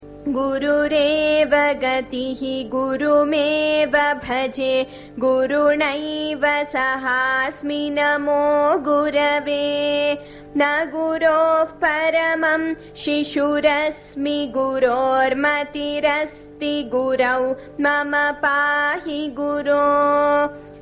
这一偈的韵律是toṭaka（诤讼），由四个sa(⌣ ⌣ ‒)构成。该颂依次使用了guru（师长）的单数八个格，方便记忆以u结尾的阳性名词的变格。